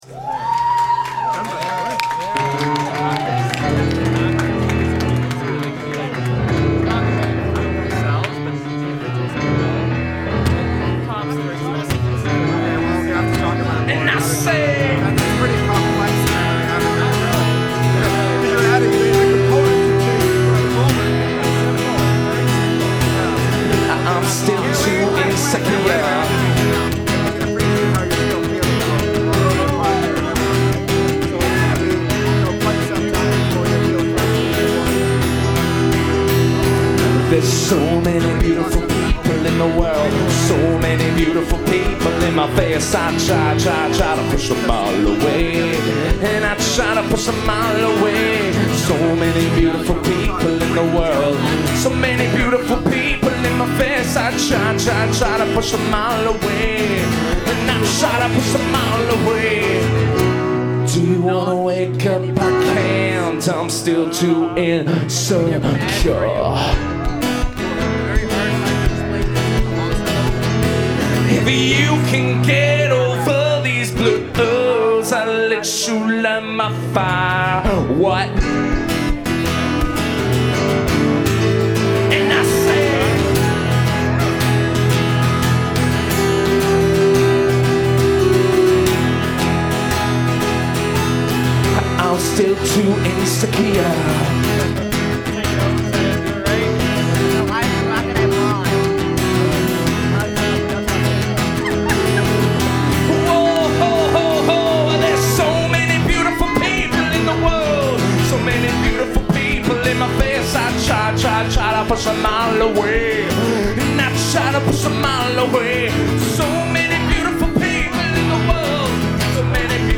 (live)
soundboard recording